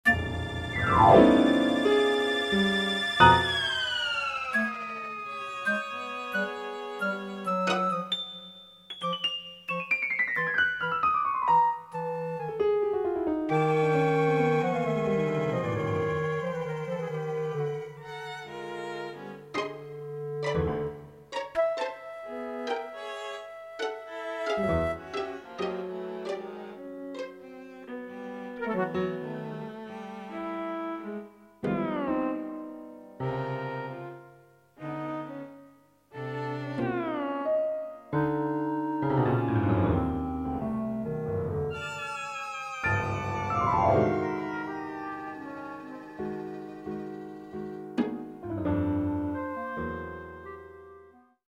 audio 44kz stereo